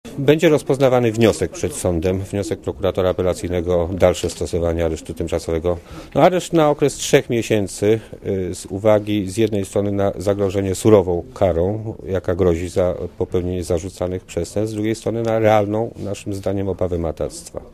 Mówi prokurator Włodzimierz Krzywicki